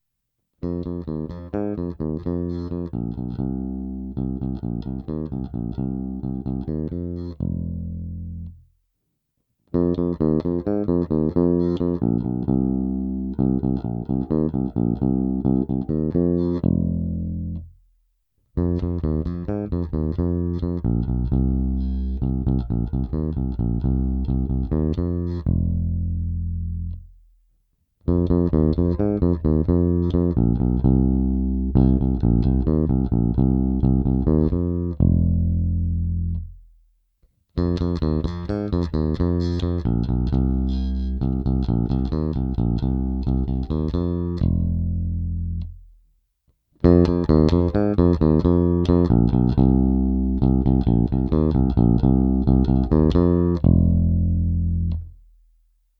Následující nahrávky jsem provedl rovnou do zvukové karty a jen normalizoval, jinak ponechal bez jakéhokoli postprocesingu.
(1) korekce ve střední poloze
(2) naplno přidané středy
(3) naplno přidané basy
(4) naplno přidané basy a středy
(5) naplno přidané basy a výšky
(6) všechno naplno
Vliv korekcí – normalizováno jako celek